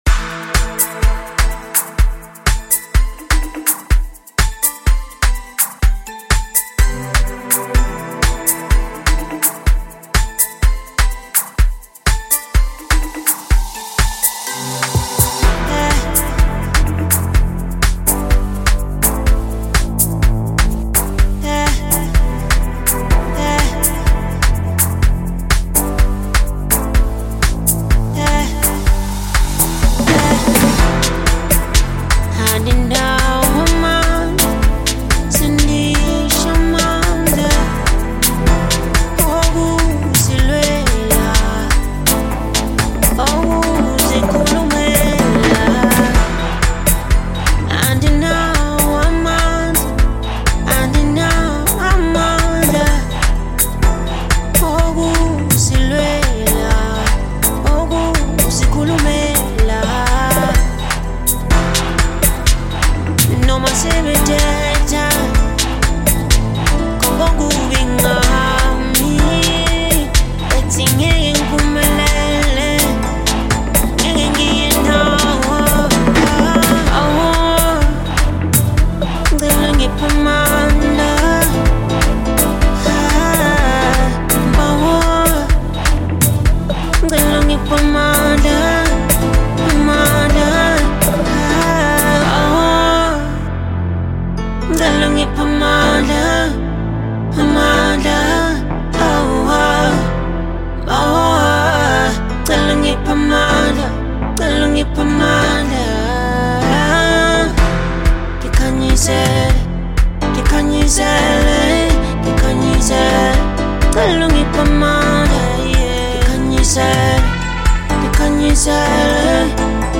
new house track filled with good vibes